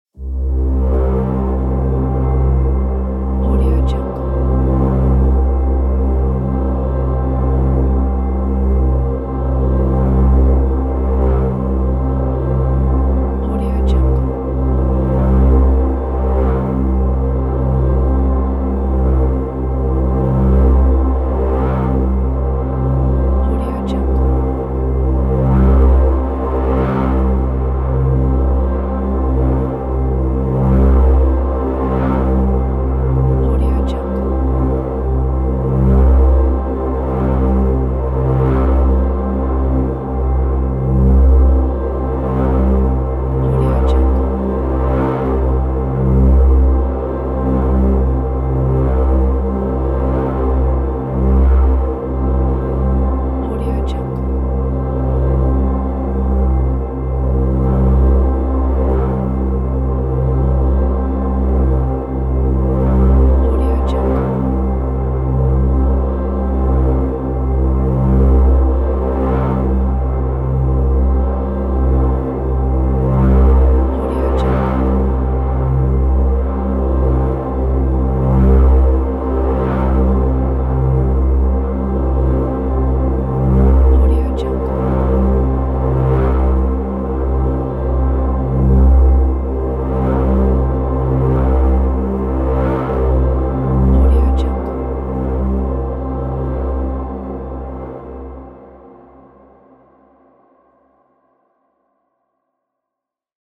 دانلود افکت صدای پهپاد فضایی
افکت صدای پهپاد فضایی یک گزینه عالی برای هر پروژه ای است که به صداهای آینده نگر و جنبه های دیگر مانند پهپاد، محیط و پس زمینه نیاز دارد.
Sample rate 16-Bit Stereo, 44.1 kHz
Looped No